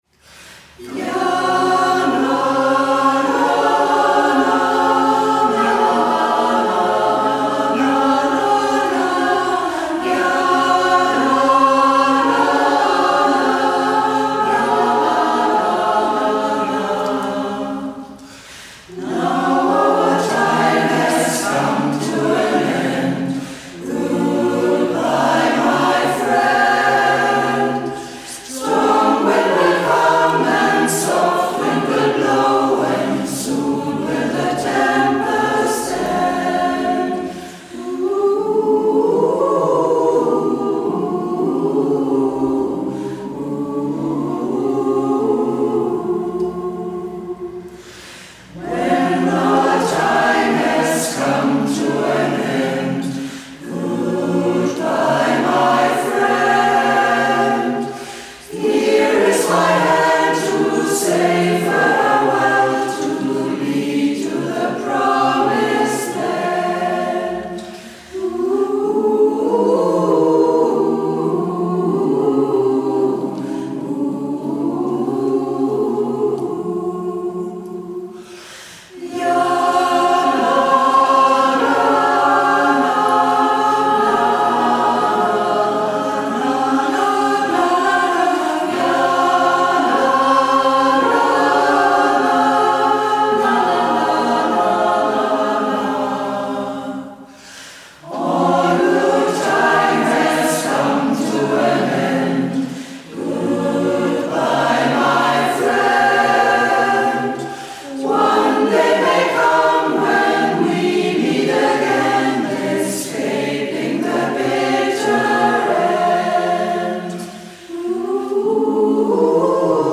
Workshop 2025
Aufnahmen von den Proben